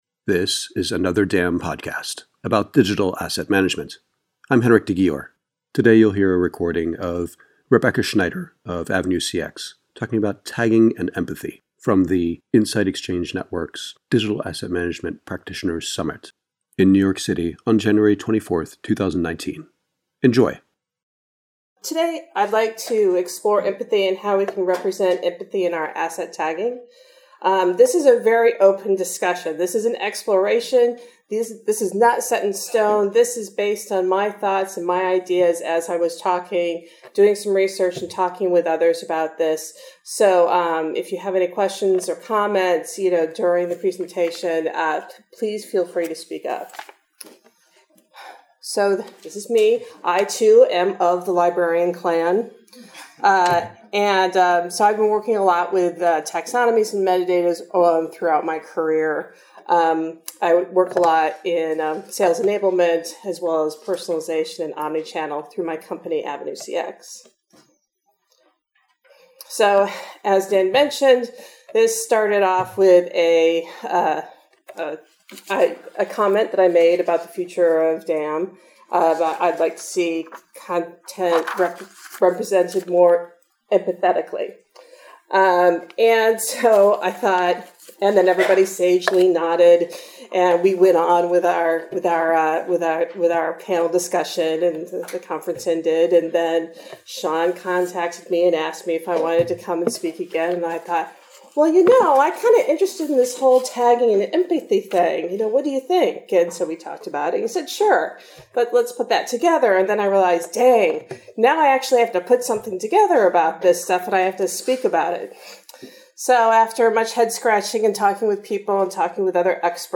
Another DAM Podcast interview